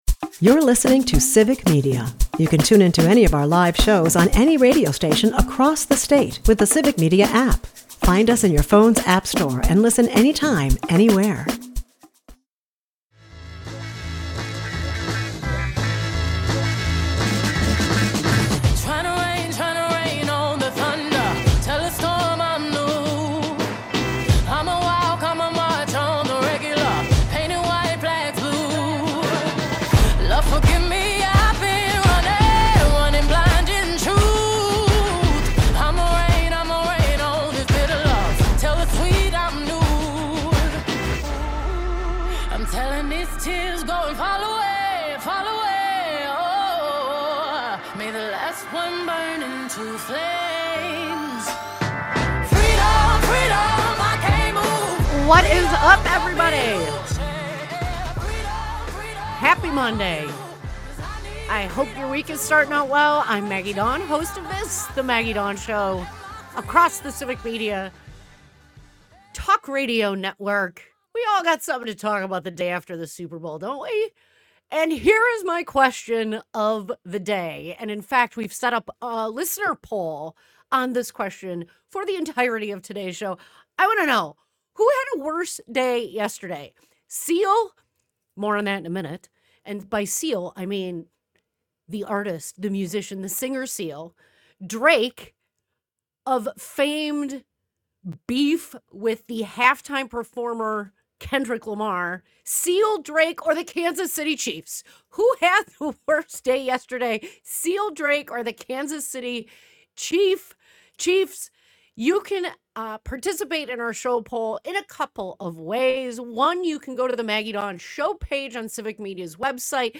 Broadcasts live, 2 - 4 p.m. across Wisconsin.